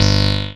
BASS04.WAV